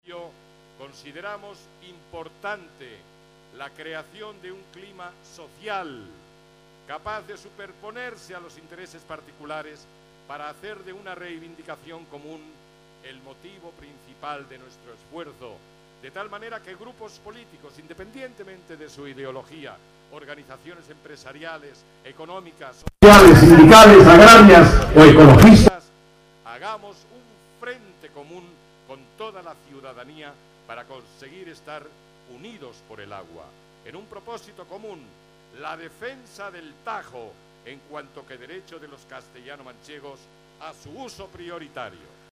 Como rúbrica de la concentración y movilización ciudadana en defensa del agua, el periodista y presentador Constantino Romero, leyó el manifiesto del agua firmado por un millar de asociaciones y colectivos de Castilla-La Mancha. En dicho manifiesto se reconoce como un derecho del territorio el disfrutar de agua en cantidad y calidad y se exige, entre otras cuestiones, que el Plan de Cuenca del Tajo tenga en cuenta el mantenimiento de los caudales ecológicos, los crecimientos demográficos presentes y futuros, los desarrollos económicos e industriales y la mejora y ampliación de los regadíos.